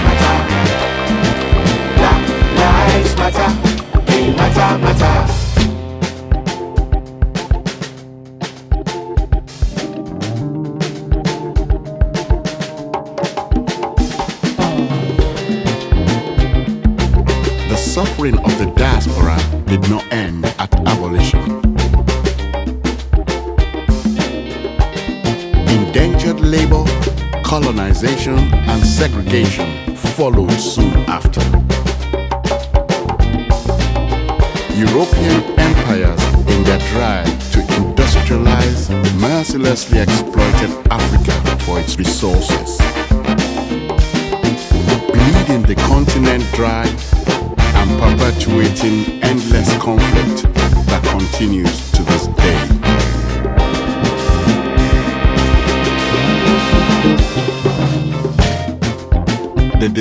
ジャンル(スタイル) SOULFUL HOUSE / AFRO HOUSE